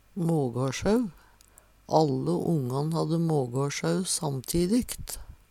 mågåsjau - Numedalsmål (en-US)